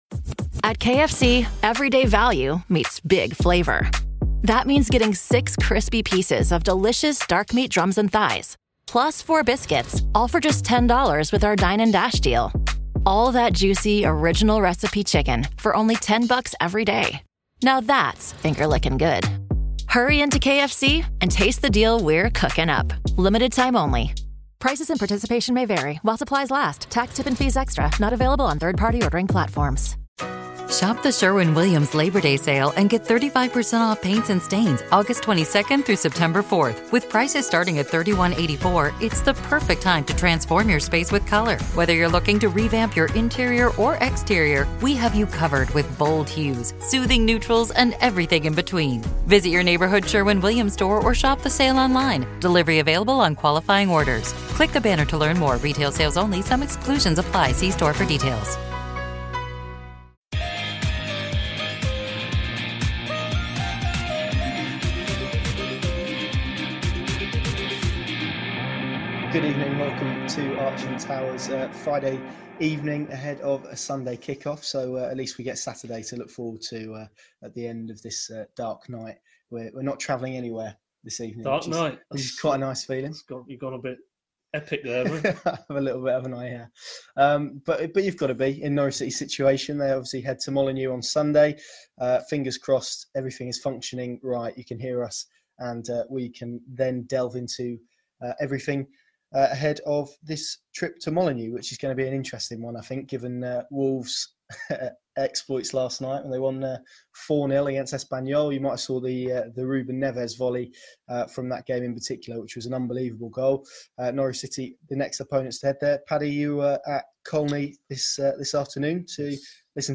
sat down to take questions from Canaries fans ahead of the trip to Molineux and to review Daniel Farke's lively pre-match press conference